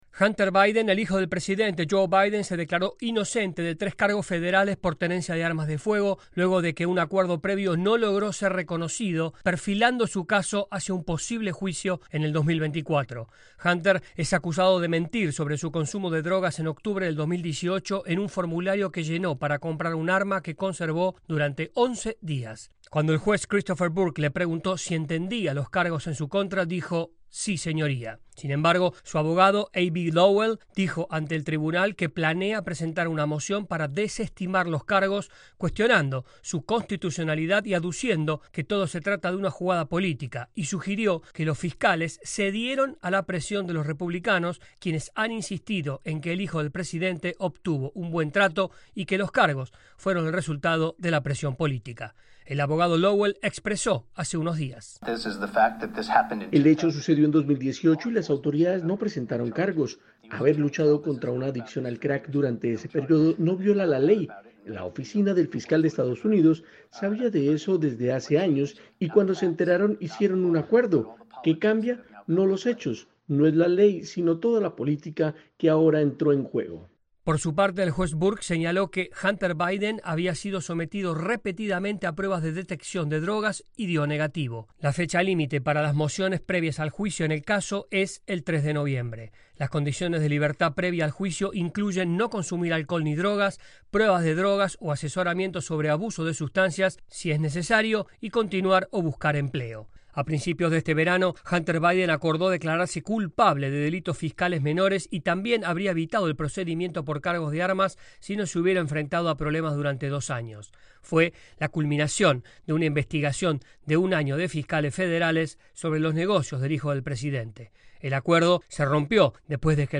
desde la Voz de América en Washington DC